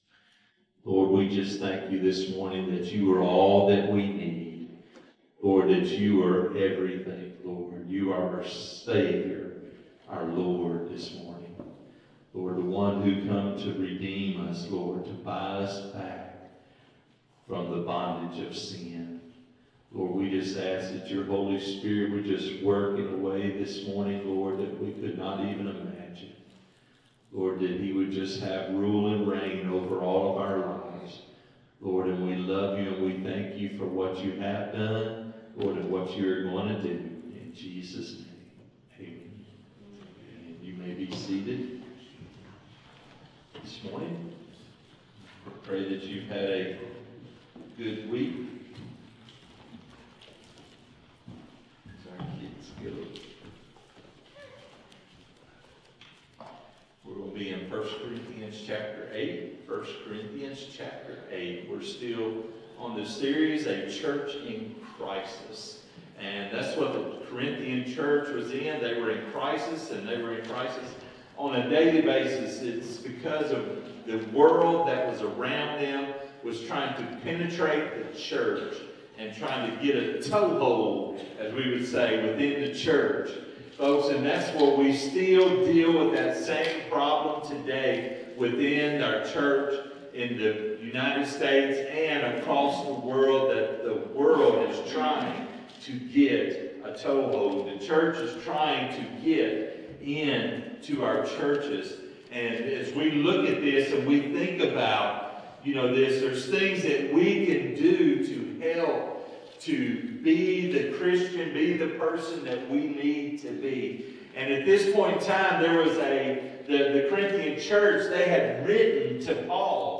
Sermons | First Southern Baptist Church Bearden